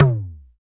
LOGTOM MD F.wav